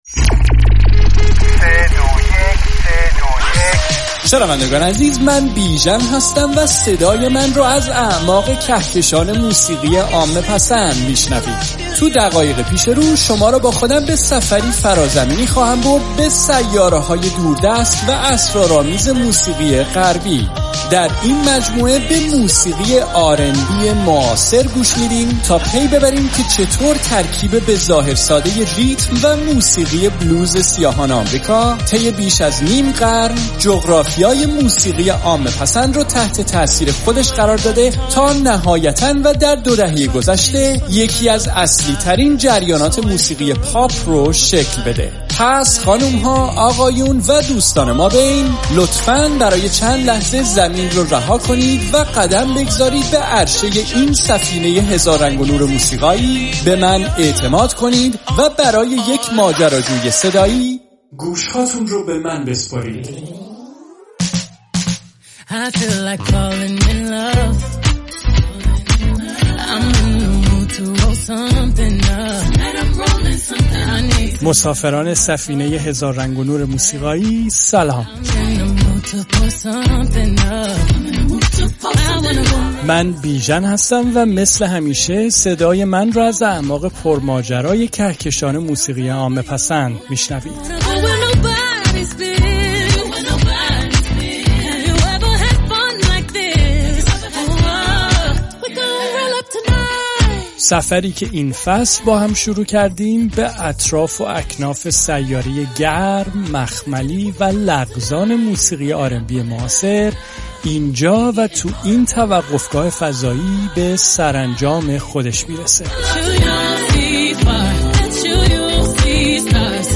موسیقی آراَندبی معاصر
هنرمندی با صدای منحصر بفرد و شخصیتی تاثیرگذار.